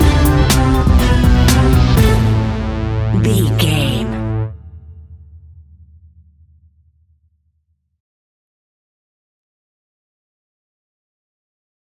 Quirky Halloween Music Stinger.
Aeolian/Minor
eerie
groovy
funky
electric organ
synthesiser
drums
strings
percussion
spooky